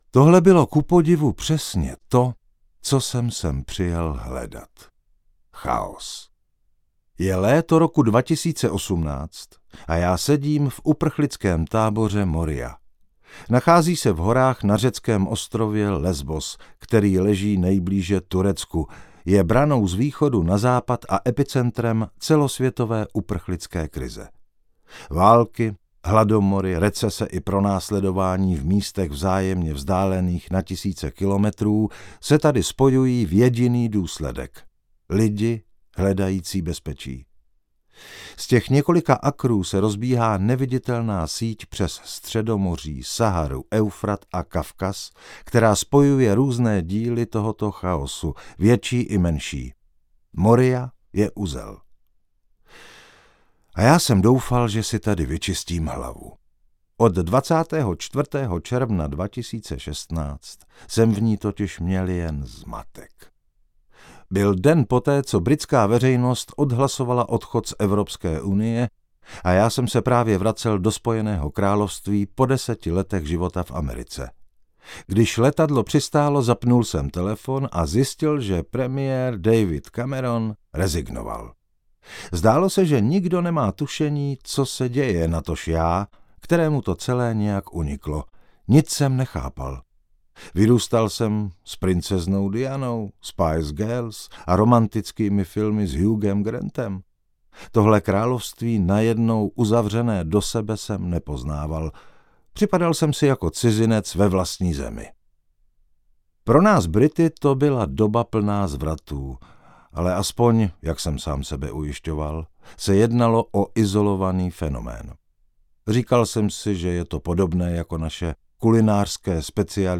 Audiokniha Cenové války - Rupert Russell | ProgresGuru